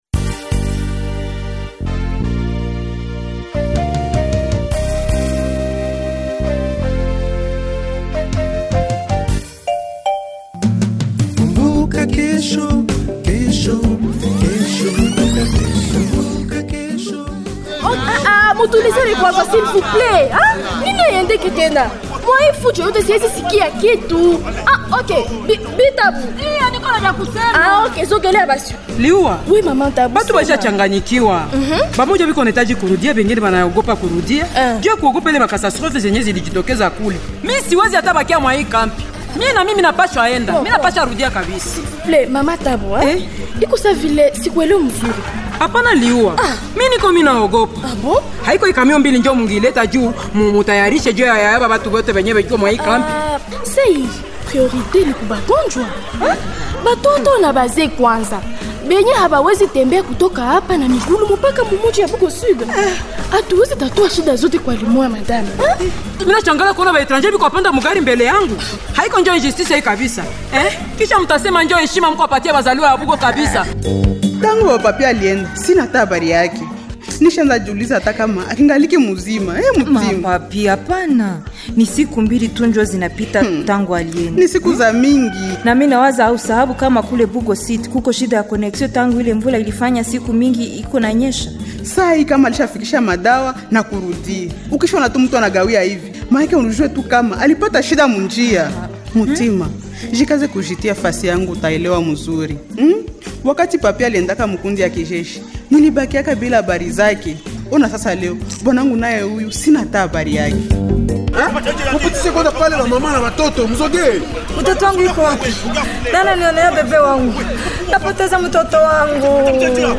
Voici le feuilleton Kumbuka Kesho du 12 au 19 janvier 2026